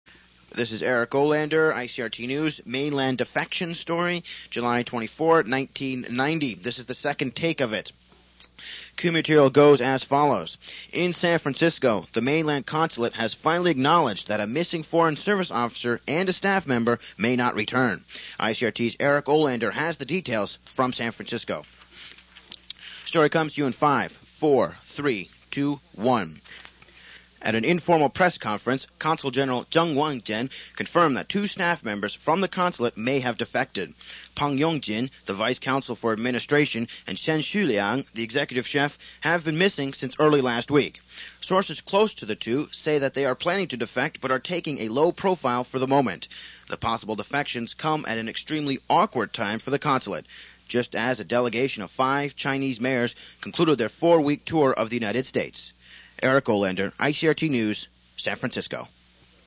Later, I got the chance to anchor, produce and report from the US and Taiwan for the station.